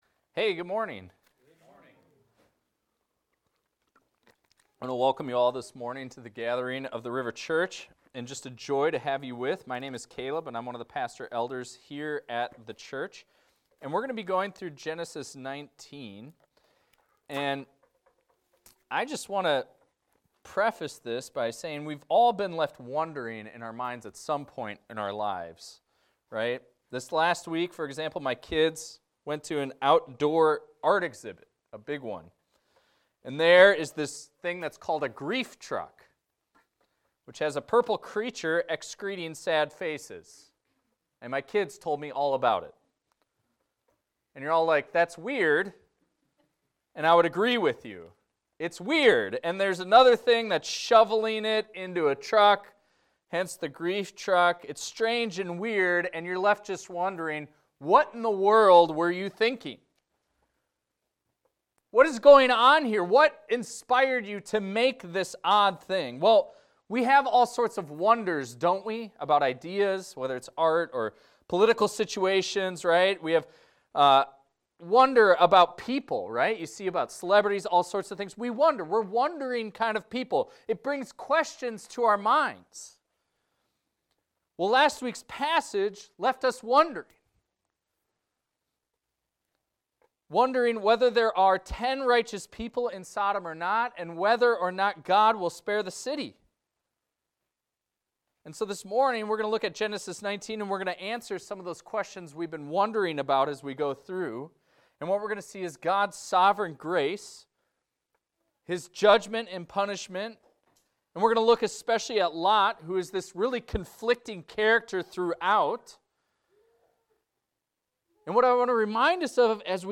This is a recording of a sermon titled, "Faith vs. Sight."